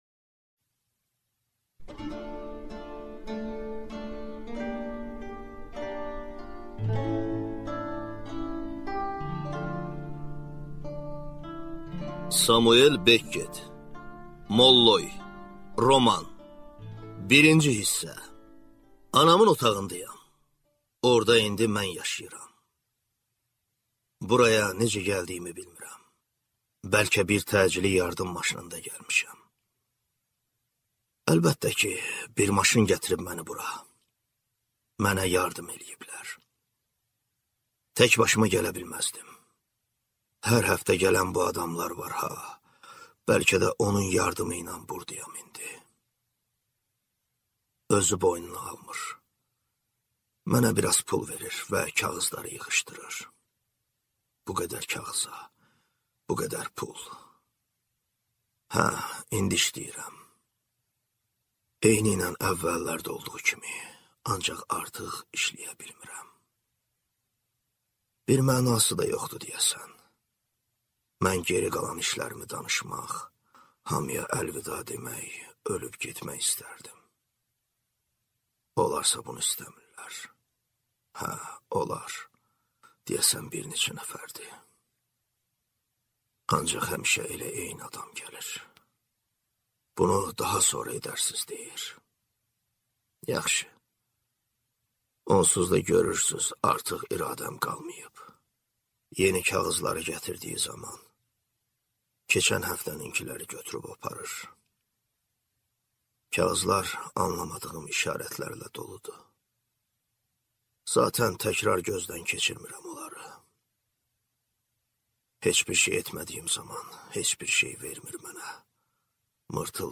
Аудиокнига Molloy | Библиотека аудиокниг